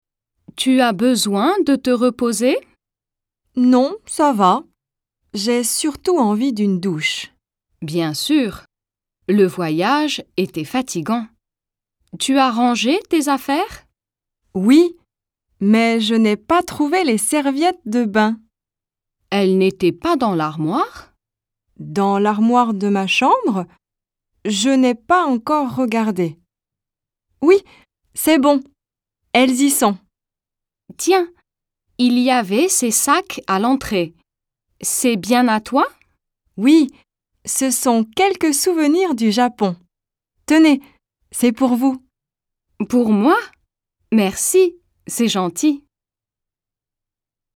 DIALOGUE :